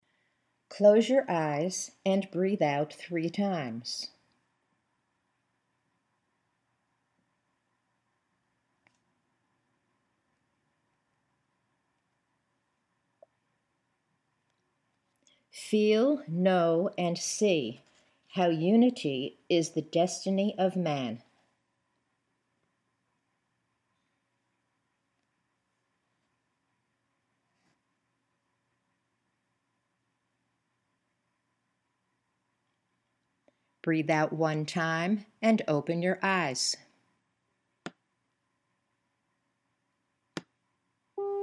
There are ten seconds of silence on the tape for your breathing, followed by the Imagery exercises.
You’ll notice silences on the audio after the breathing instructions, typically ten seconds for breathe out three times and fewer seconds for fewer breaths.